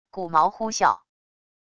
骨矛呼啸wav音频